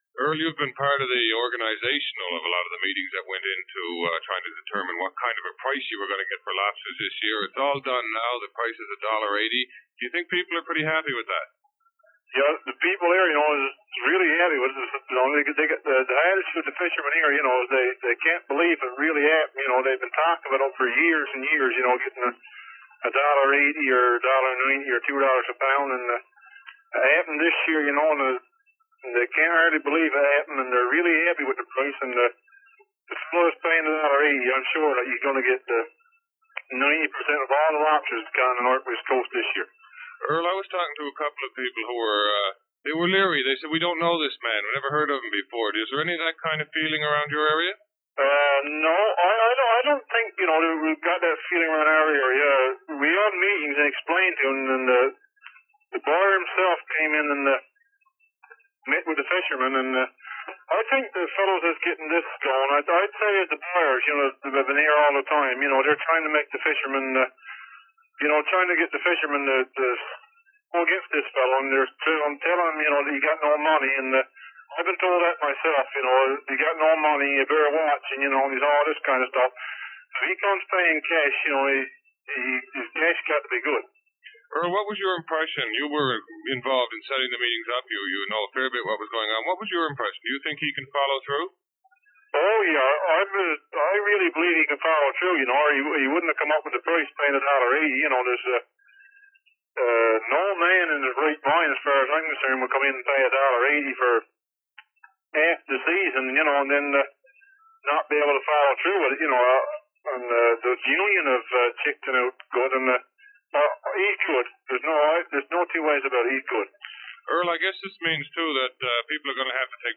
Dialects of English: Irish English volume 1 - The North of Ireland
Northwest coast